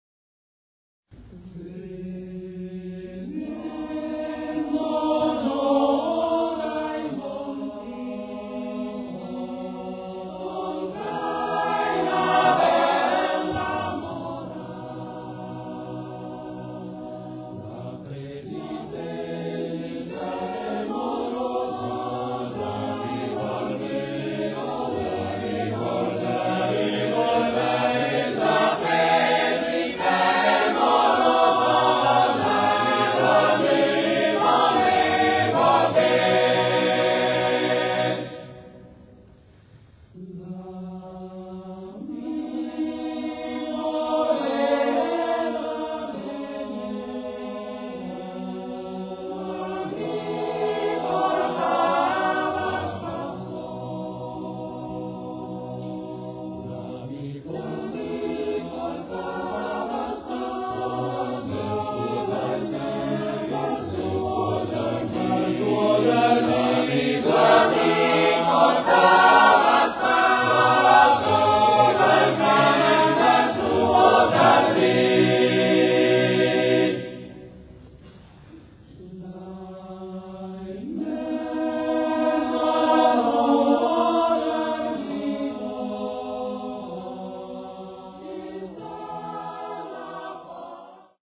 [ voci virili ]